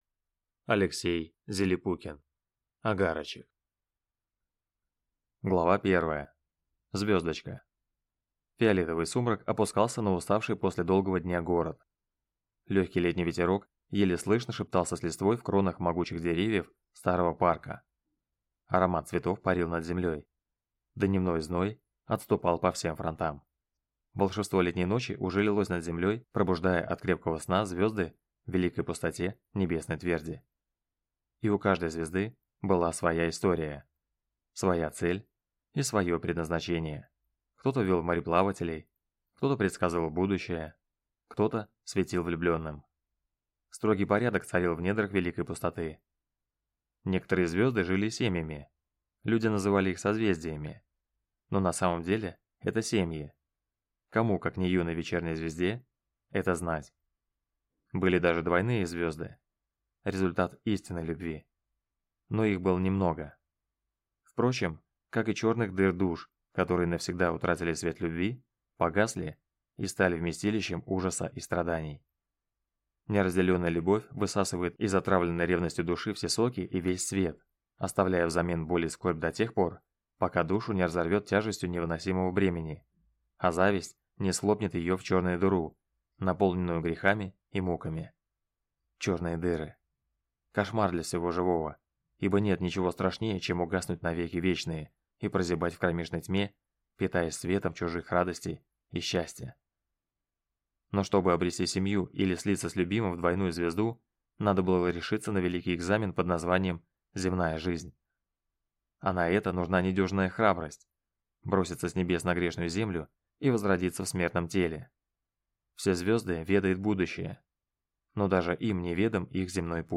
Аудиокнига Огарочек | Библиотека аудиокниг